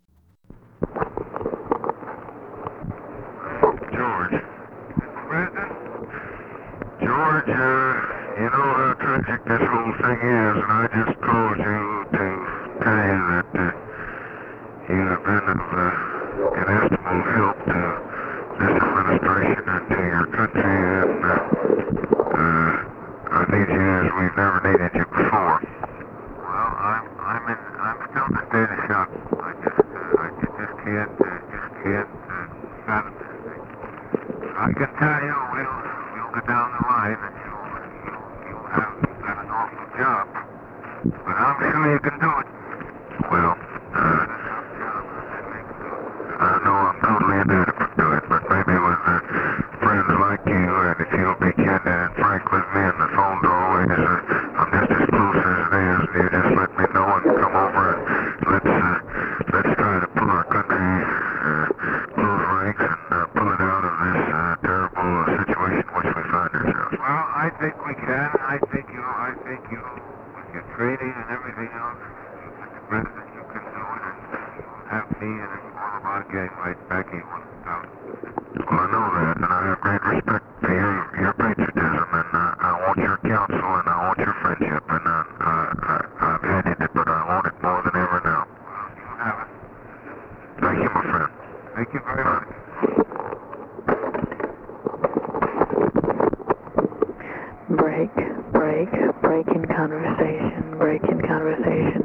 Conversation with GEORGE MEANY, November 23, 1963
Secret White House Tapes